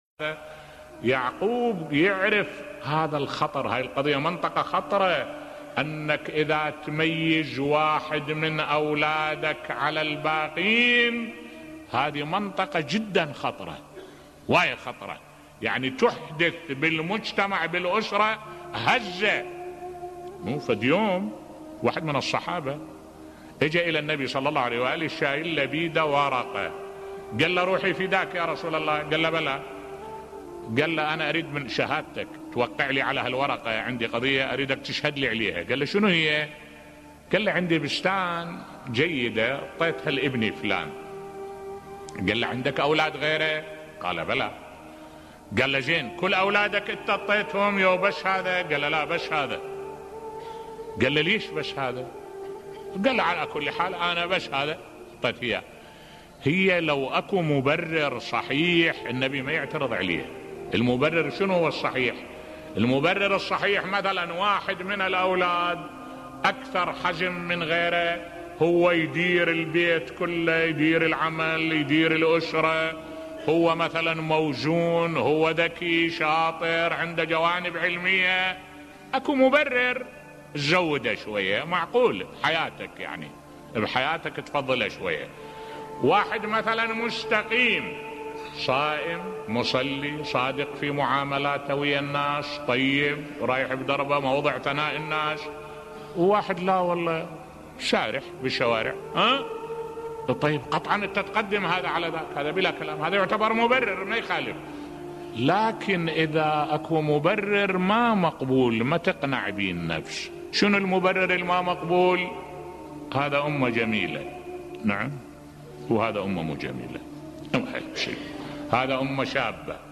ملف صوتی خطورة التفريق بين الأبناء بصوت الشيخ الدكتور أحمد الوائلي